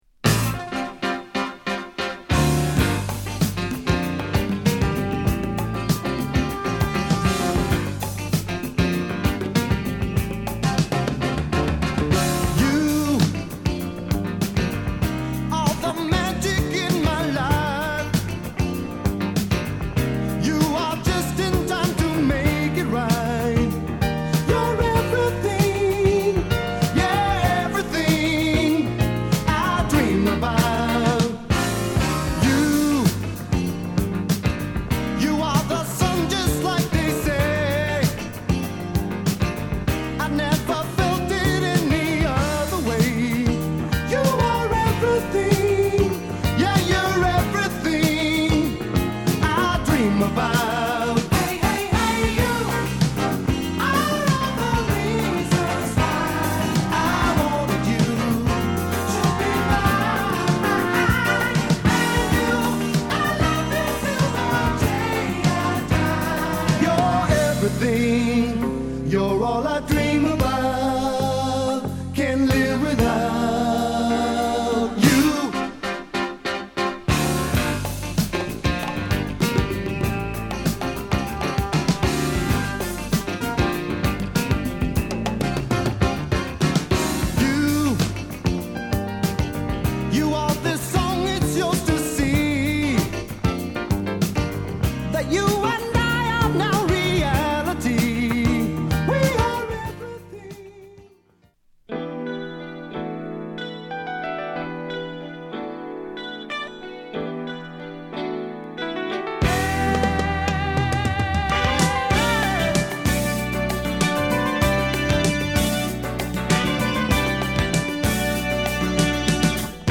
シティ・ポップの原型
洗練されたサウンドと爽快な高揚感が心地良い
レゲエのリズムを取り入れた
多少のチリチリ音はあるかと思います。